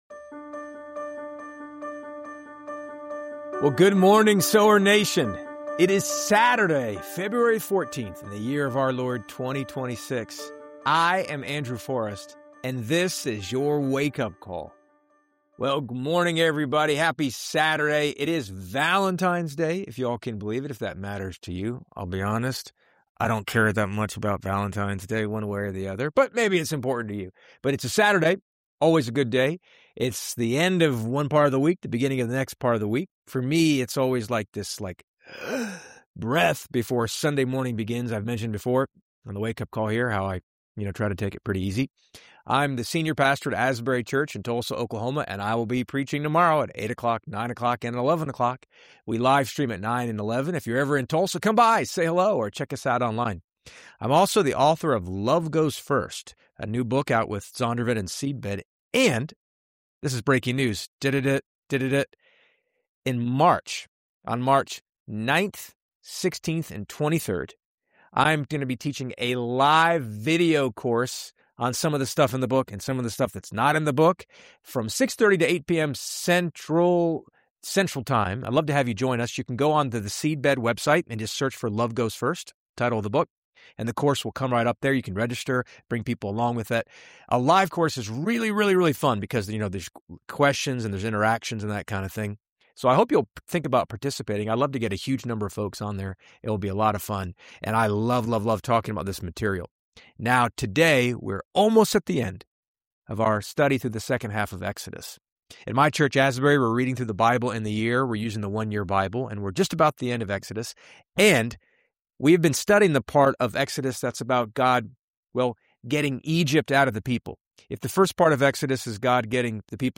Hit play and subscribe for more thought-provoking devotionals and soul-stirring conversations like this.